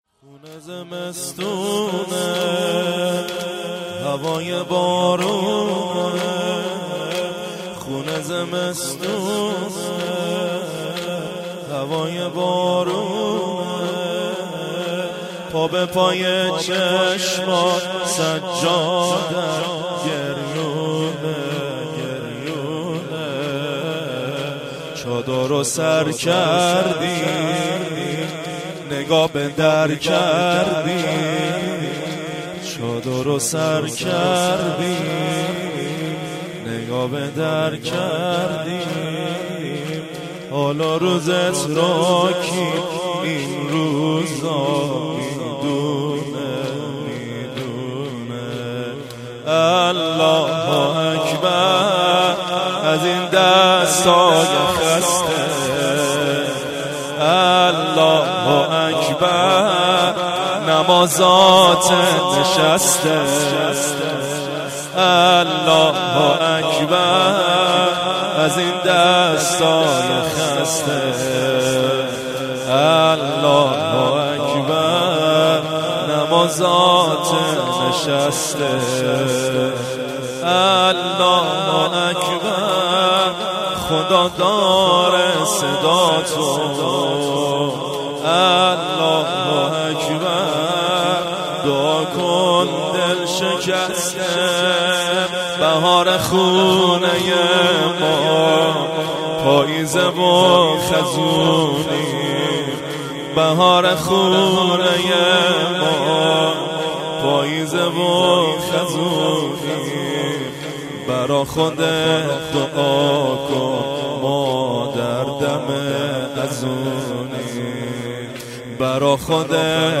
زمینه - خونه زمستونه ، هوای بارونه